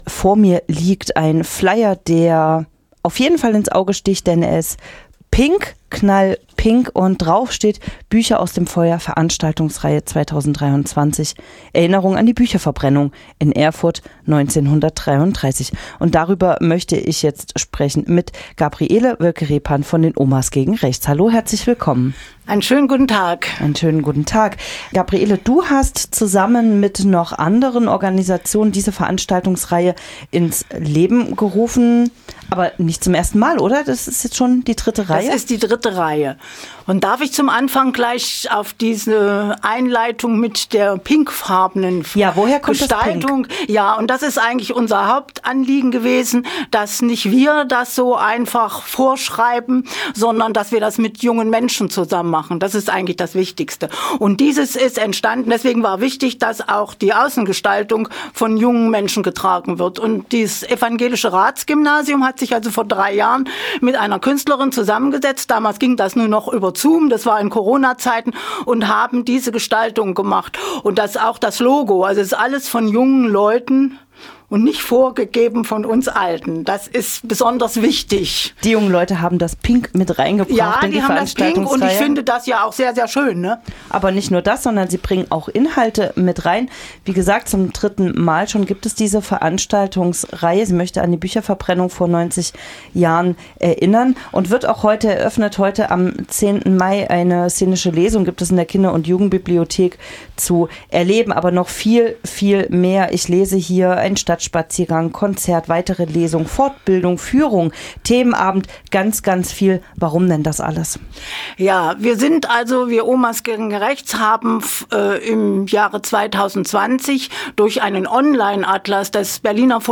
| Interview zur Reihe "Bücher aus dem Feuer"